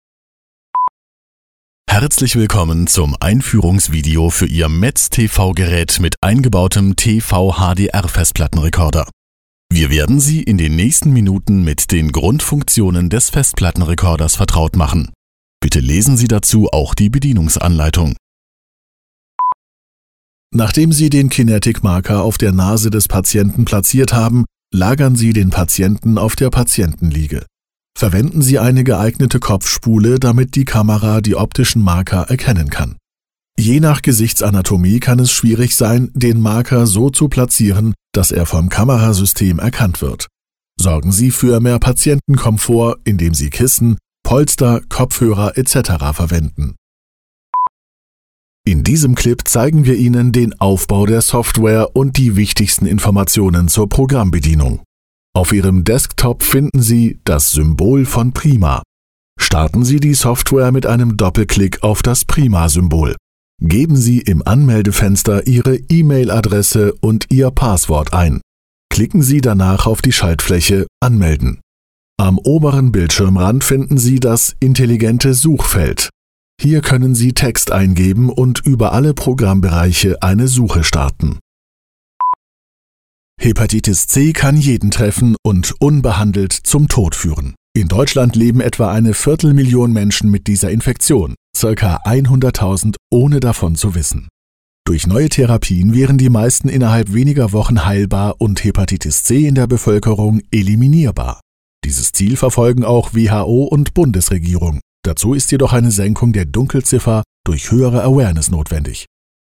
Corporate video
Middle Aged